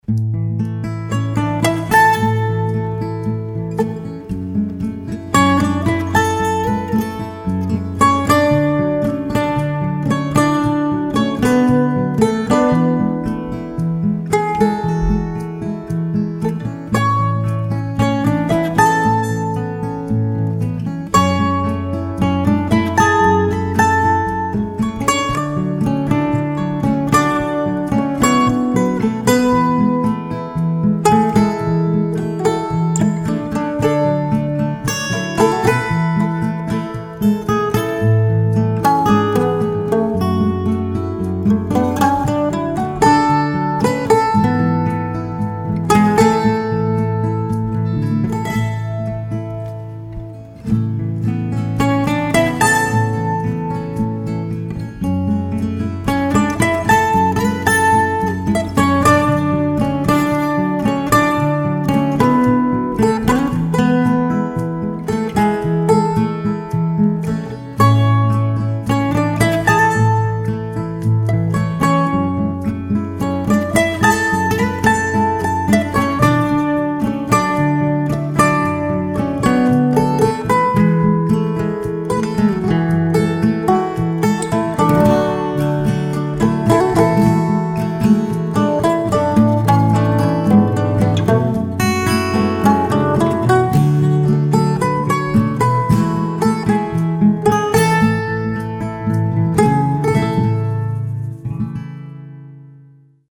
Country Stock Audio Tracks